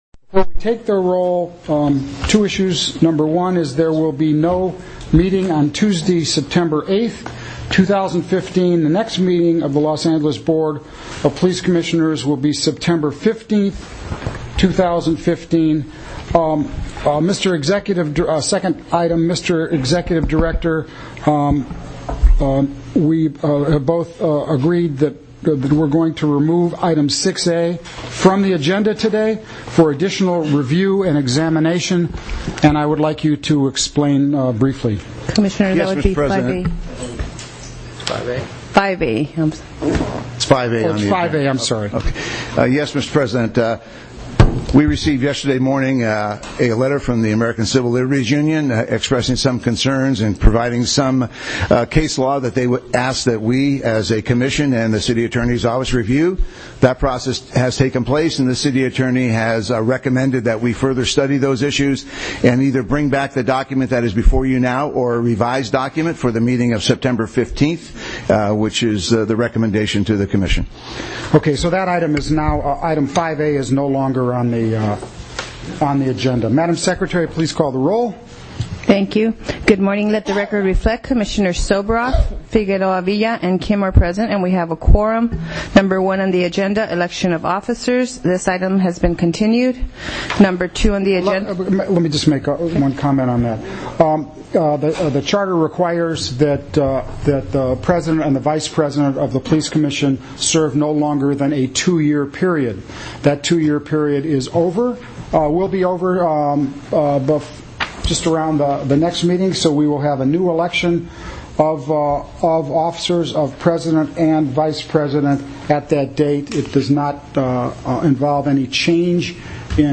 Chief Answers Media Questions 9-1-15
commission-mtg-9-1-15.mp3